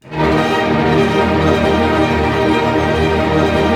Index of /90_sSampleCDs/Roland L-CD702/VOL-1/STR_Arpeggios/STR_Arpeggios